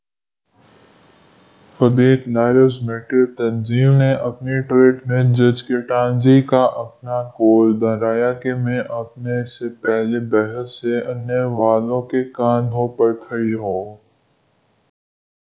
deepfake_detection_dataset_urdu / Spoofed_TTS /Speaker_17 /248.wav